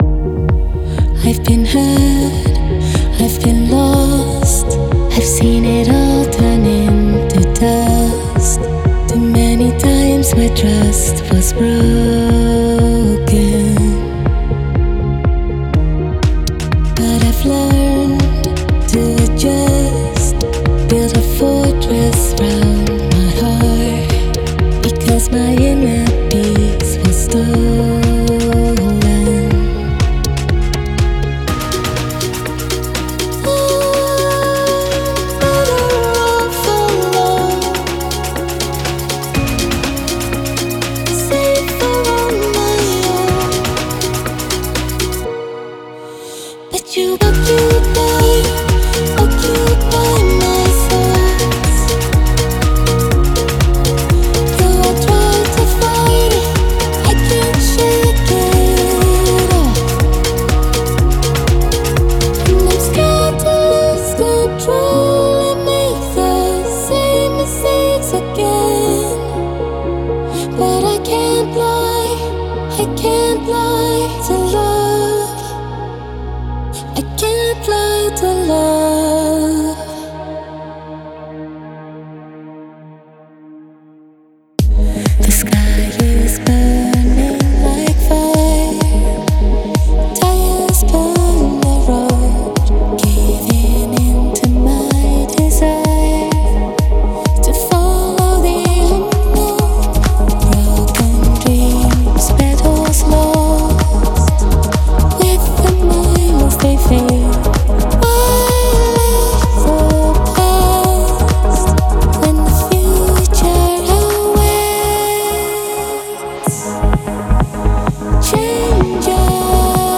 • 42 Low Harmonies（低声部和声：42 个）
• 172 Spoken One-Shots（口语单采样：172 个）
• 67 Adlib One-Shots（即兴人声单采样：67 个）